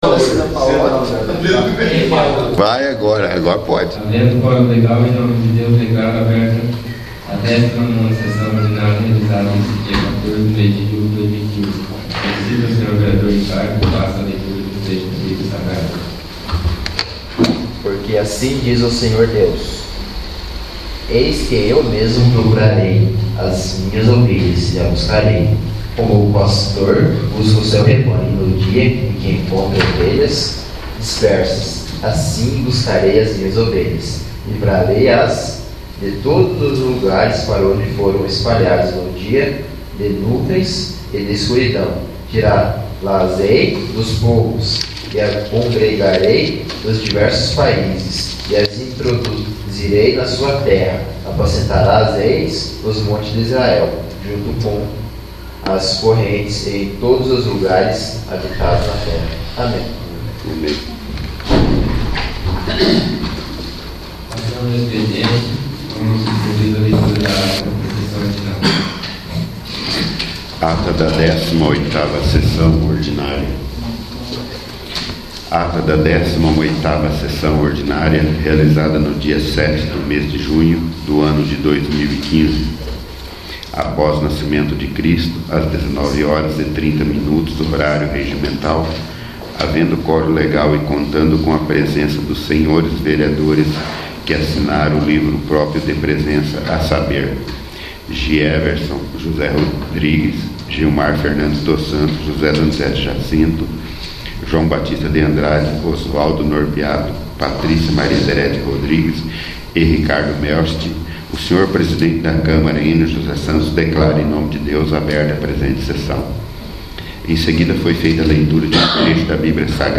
19º. Sessão Ordinária 14/07/2015
19º. Sessão Ordinária